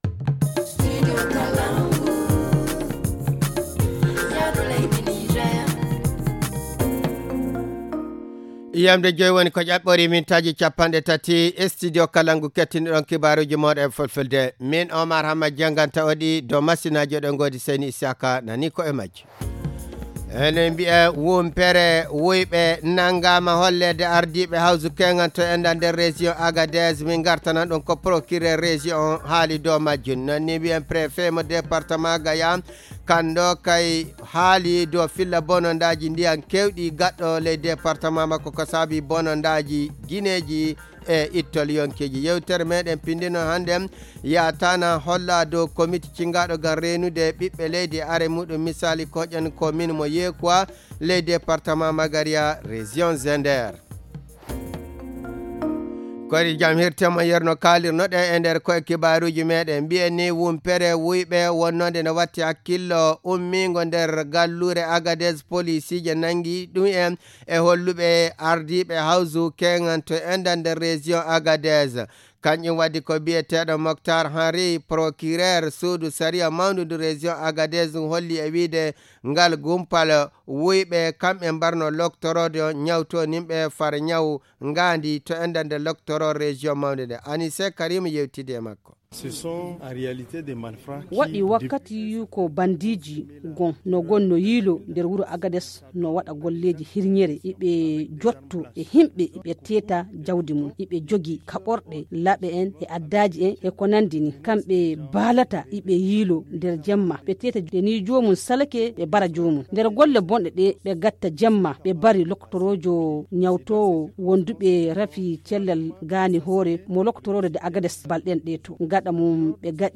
Le journal du 14 septembre 2022 - Studio Kalangou - Au rythme du Niger